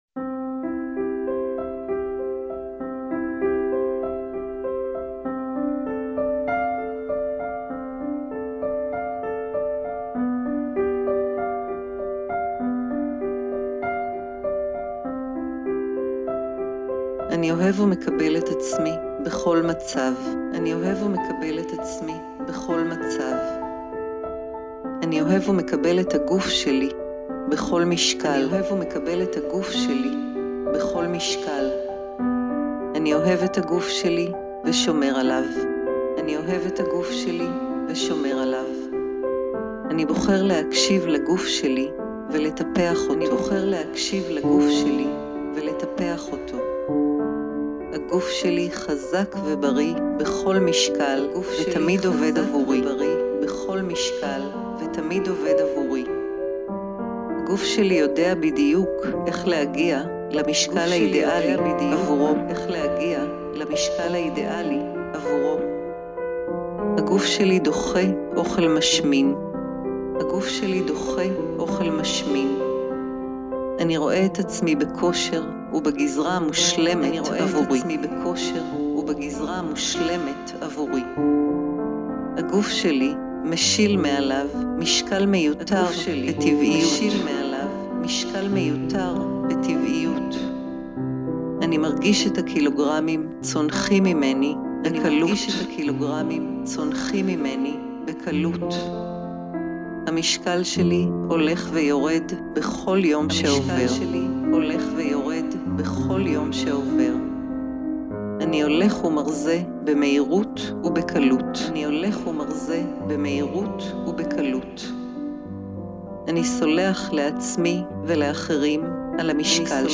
• בנוסף לקלטות הסאבלימינליות, תקבלו גם קובץ של המסרים הגלויים, כפי שהוקלטו במקור בשילוב מוזיקה נעימה.
דוגמה מתוך רצועת המסרים הגלויים להורדה במשקל לגברים: